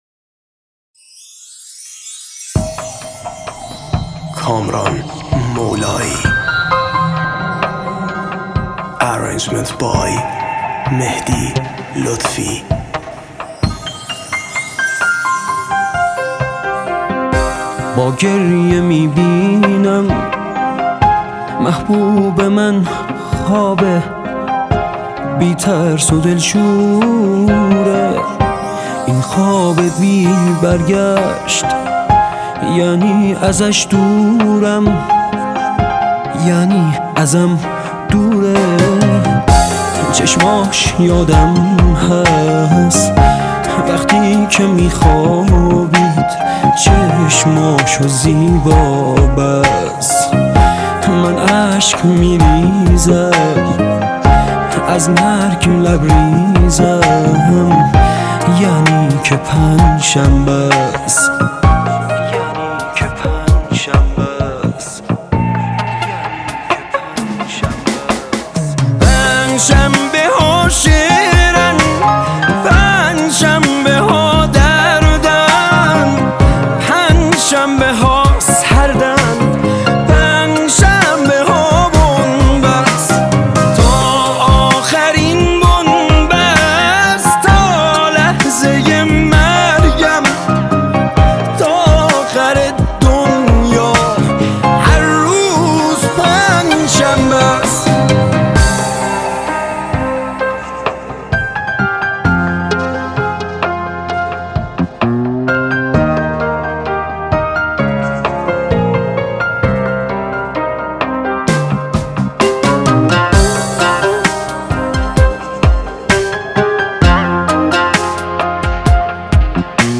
آهنگ غمگین و احساسی جدید